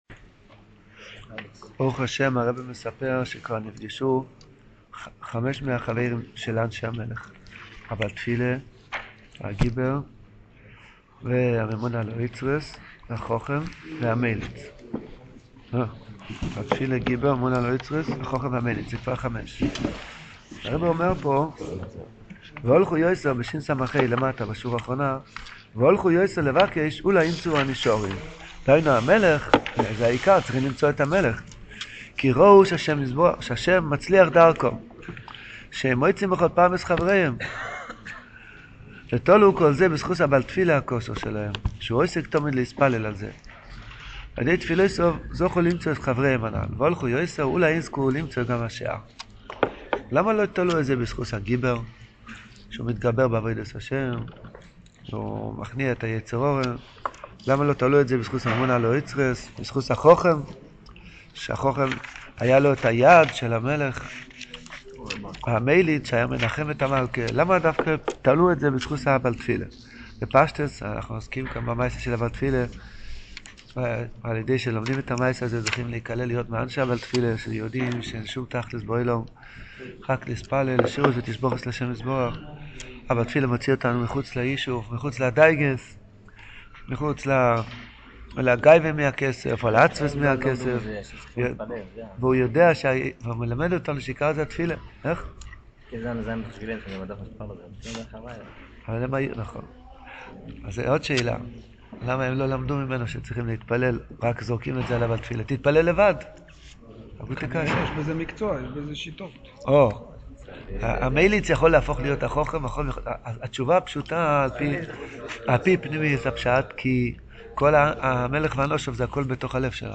This shiur is given daily after shachris and is going through each of the stories in sipurei maasios in depth. The audio quality gets better after episode 26.